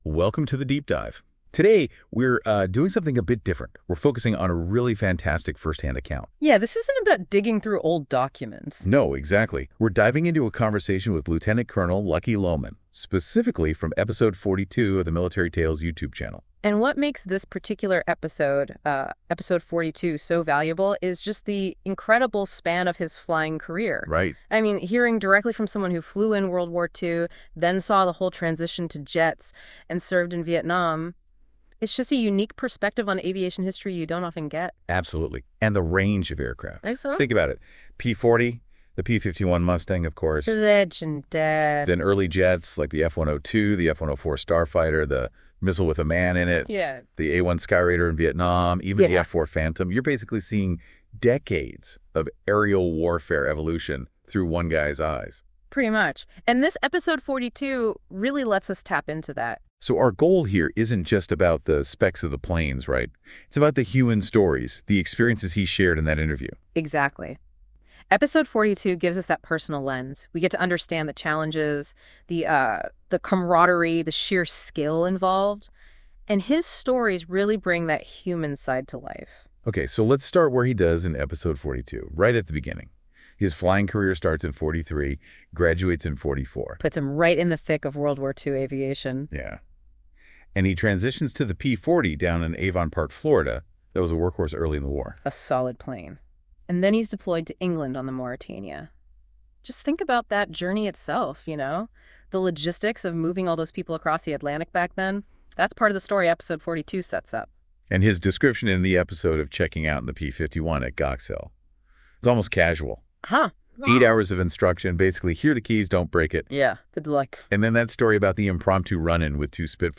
a remarkable conversation